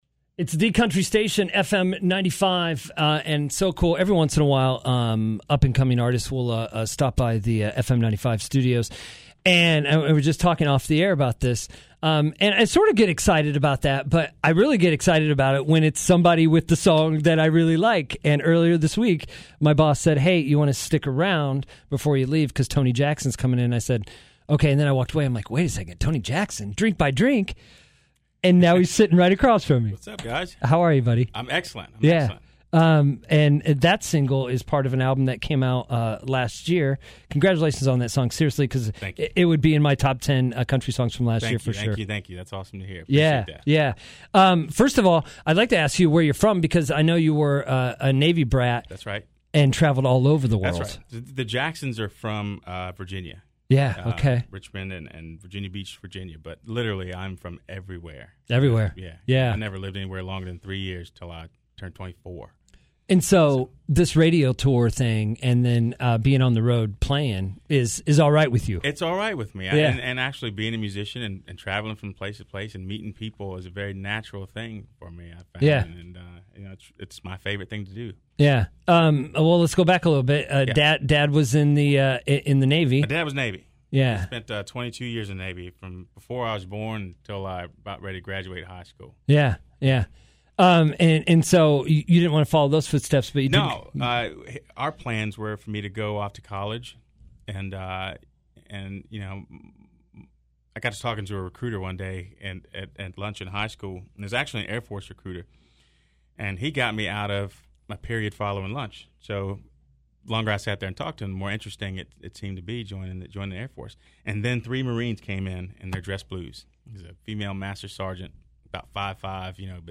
In The FM95 Studio